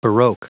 Prononciation du mot baroque en anglais (fichier audio)